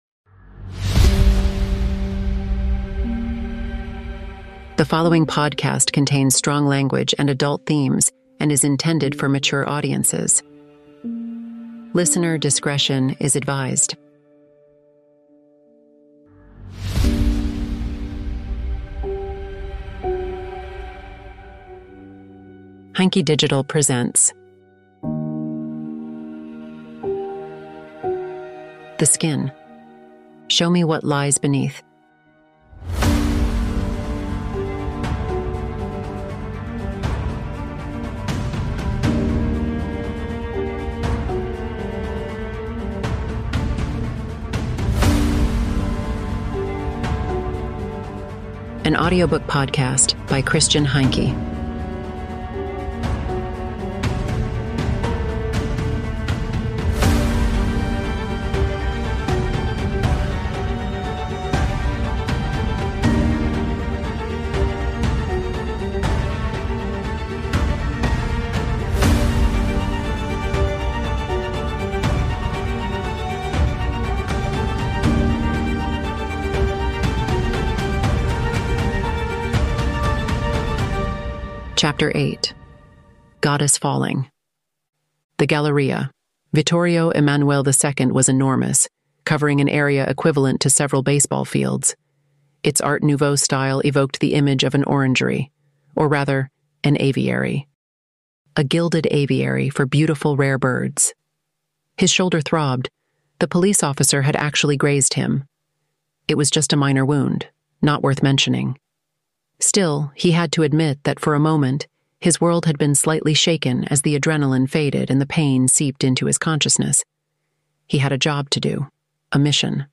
An audiobook podcast